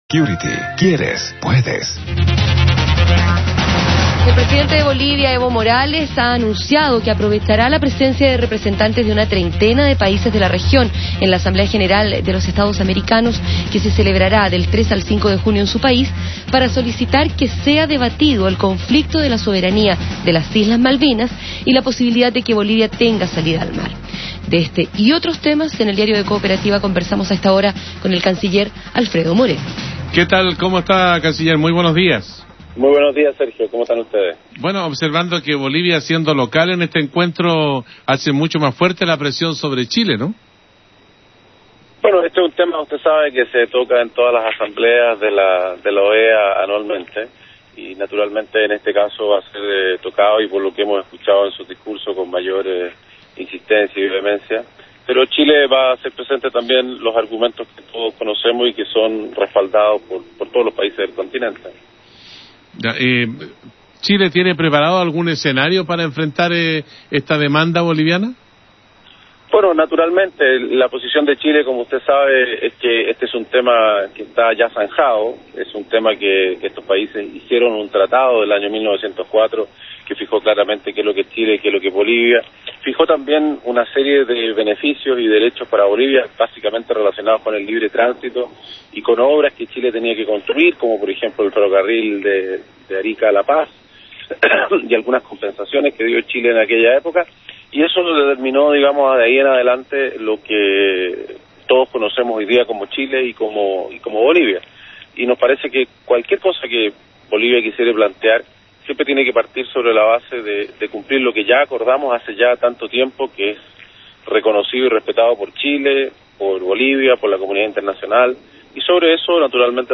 Ministerio de Relaciones Exteriores de Chile - Entrevista a Ministro Alfredo Moreno en Radio Cooperativa
entrevista_radio_cooperativa.mp3